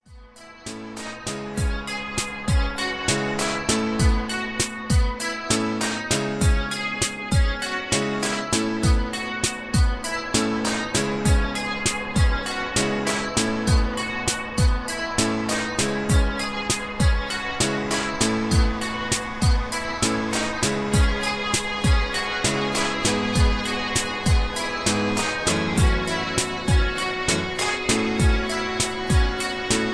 Karaoke MP3 Backing Tracks
Just Plain & Simply "GREAT MUSIC" (No Lyrics).
Tags: karaoke , mp3 backing tracks